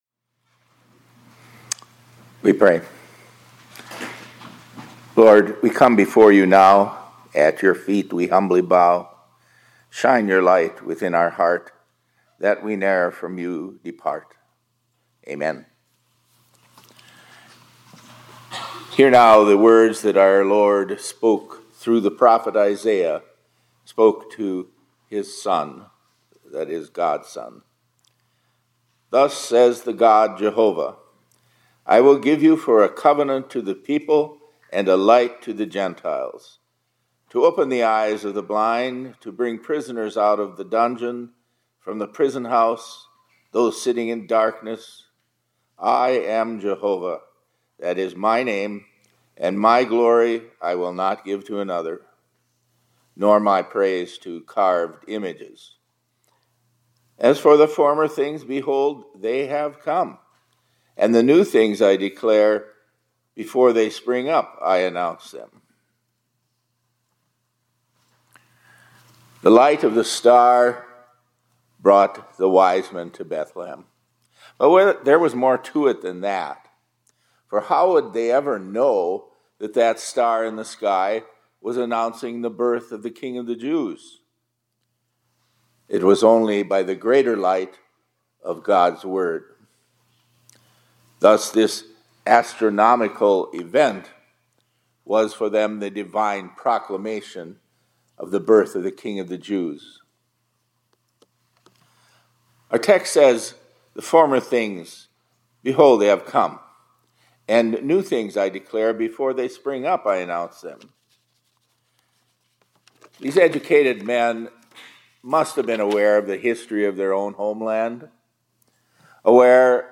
2025-01-16 ILC Chapel — Like the Magi, We are Led by the Light of the Word